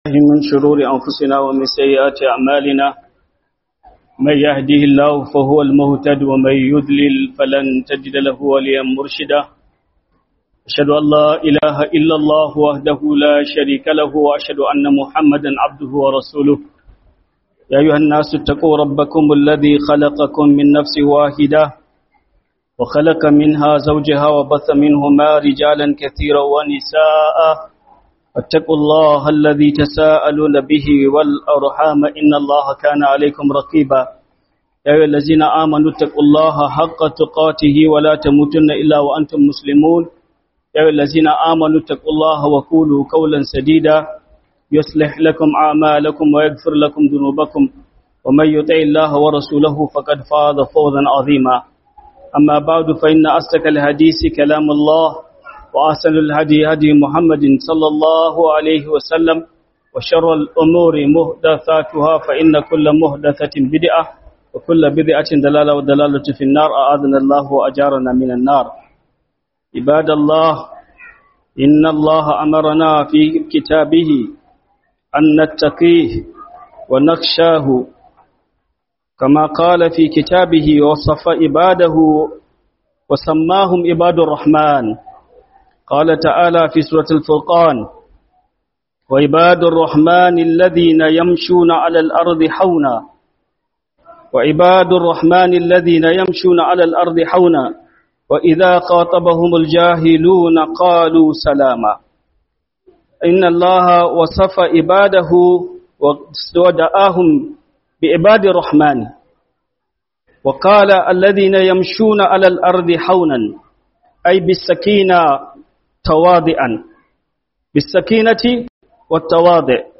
Huduba - Siffofin Bayin Allah Mai Rahama (Ibadur-Rahman)